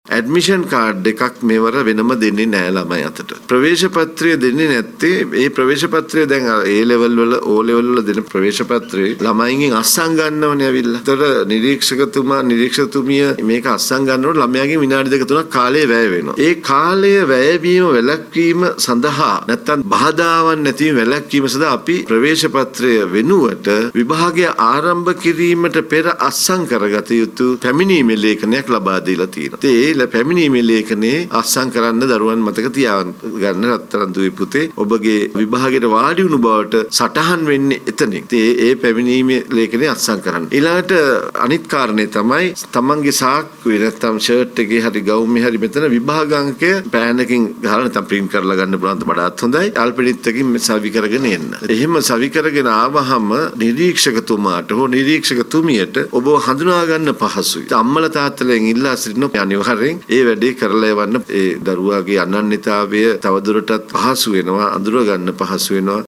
මේ විභාගය පිලිබදව පසුගියදා පැවති මාධ්‍ය හමුවකදී අදහස් පල කළ විභාග කොමසාරිස් ජනරාල් එල්.එම්.ඩී. ධර්මසේන මහතා.